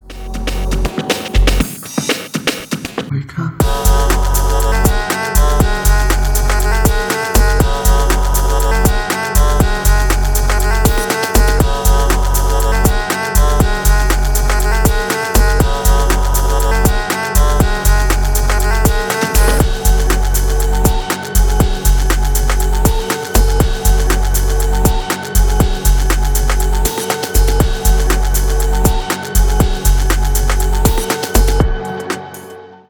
Электроника
без слов